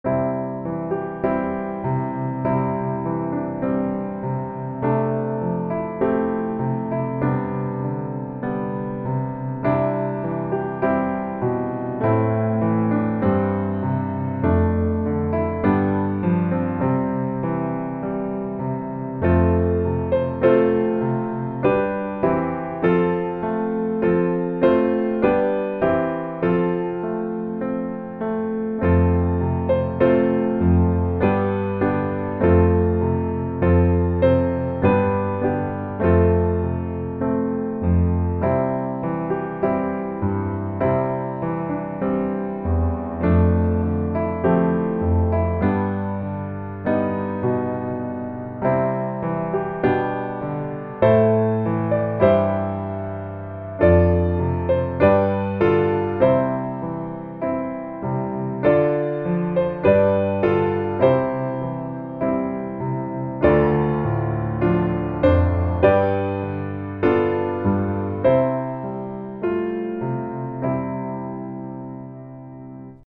C Dur